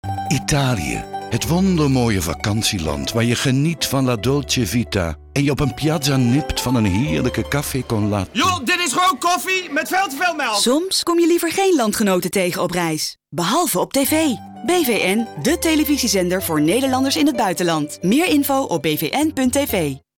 De nieuwe campagne voor BVN Nederland, die bestaat uit 1 TV-spot en 3 radiospots, toont perfect aan dat Nederlanders liever geen landgenoten zien in het buitenland ... behalve op TV via BVN.